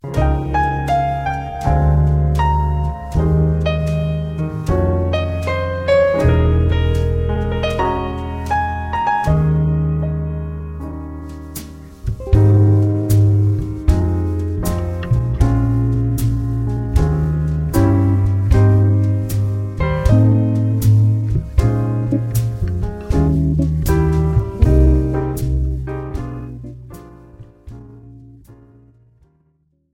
This is an instrumental backing track cover.
• Key – F
• Without Backing Vocals
• No Fade